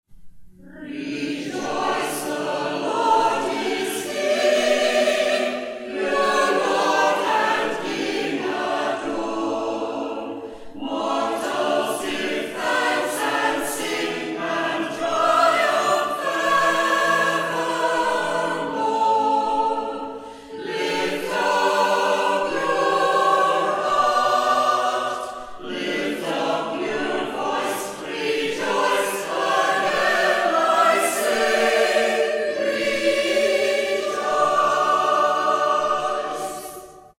Maybole Churches have a long history of Choral Singing.
Praise music performed in these historic buildings.